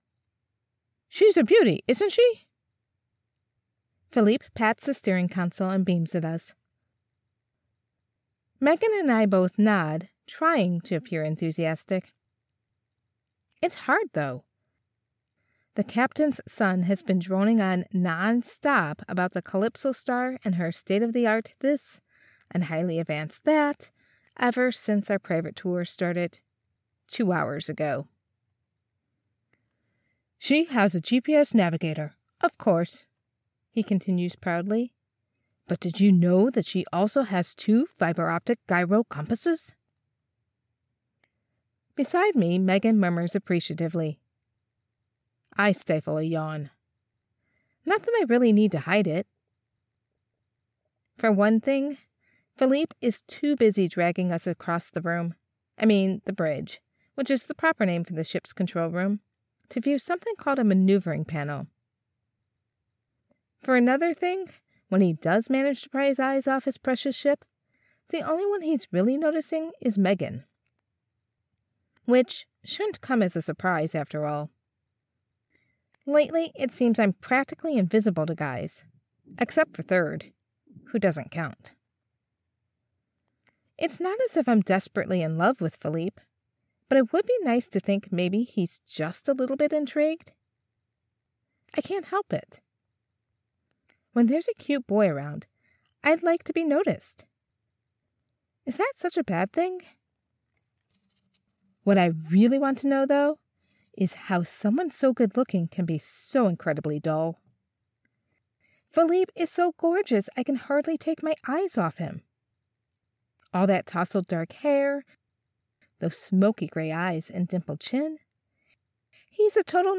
Home Studio Specs: AT2020USB+ Cardioid Condenser USB Mic, Audacity, DropBox or WeTransfer.
YA - Holiday | 1st | F/M | Bored, Flirty
Warm, Grounded, Midwest accent
Clear and intuitive